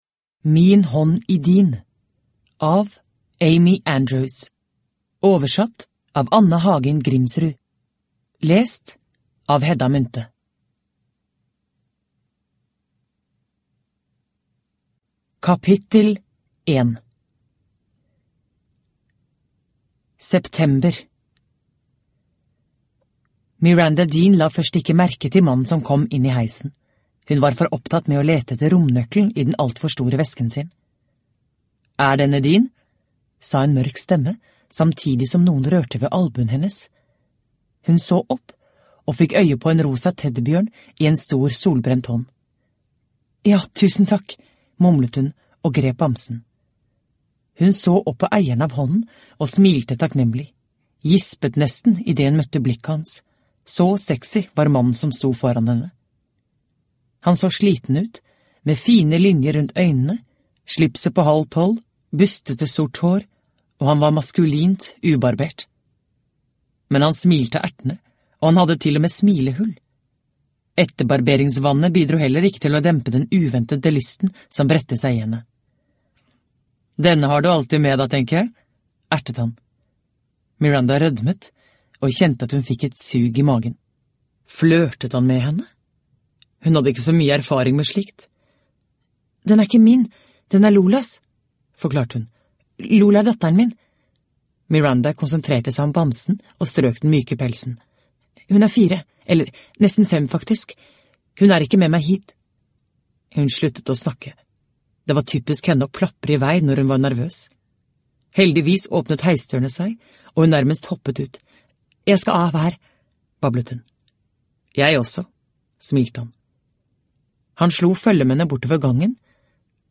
Min hånd i din – Ljudbok – Laddas ner